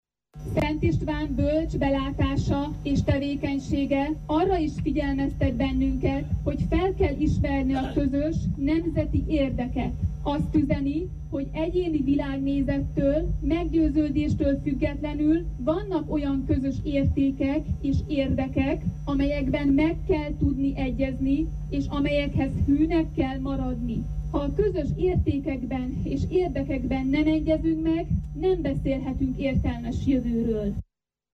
Tegnap este a Színház téren több száz ember vett részt azon a rendezvényen, amelyet az Erdélyi Magyar Nemzeti Tanács Maros megyei szervezete szervezett.
Flender Gyöngyi konzul beszédében hangsúlyozta: a magyar közösségek jövője azon is múlik, hogy mennyire képesek összefogni a közös értékek és közös érdekek mentén: